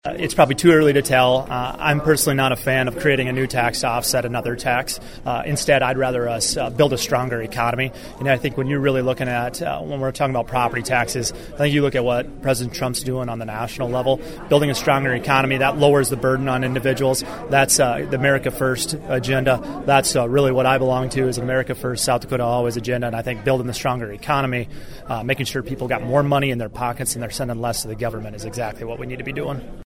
Crabtree appeared at a meeting of “Republican Friends” in Yankton.